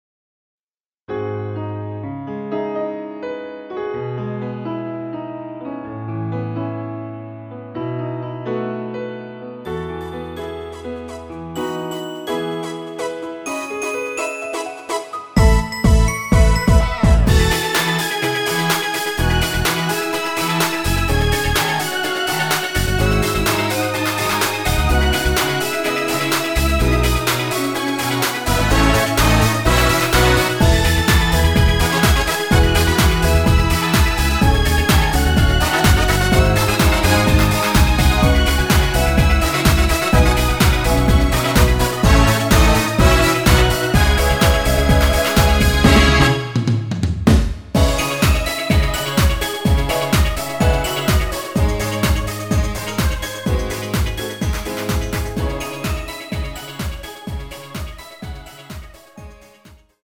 여자키 멜로디 포함된 MR 입니다.(미리듣기 참조)
앞부분30초, 뒷부분30초씩 편집해서 올려 드리고 있습니다.